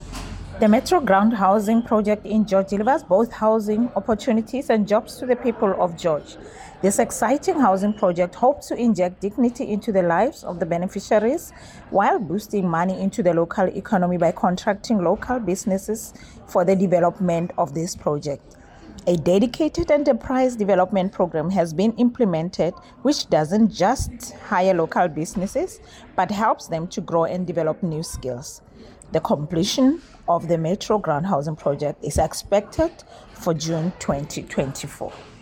attached an audio clip by MPP Matlhodi Maseko